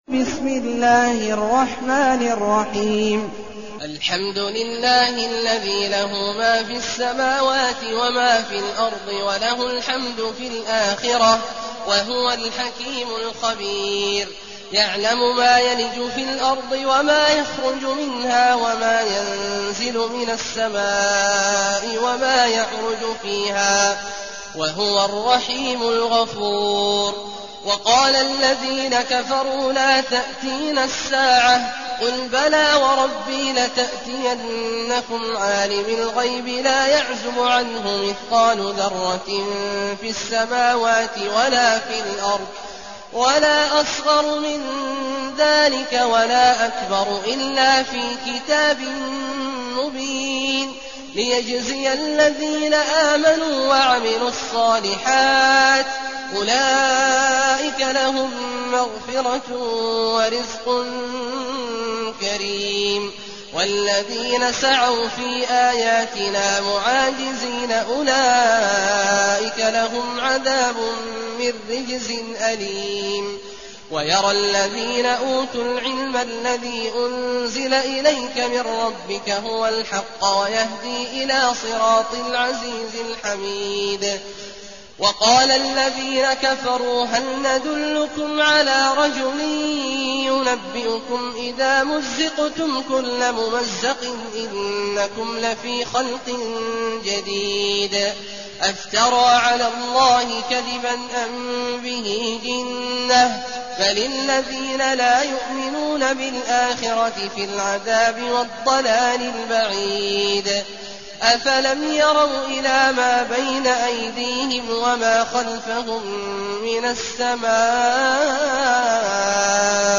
المكان: المسجد النبوي الشيخ: فضيلة الشيخ عبدالله الجهني فضيلة الشيخ عبدالله الجهني سبأ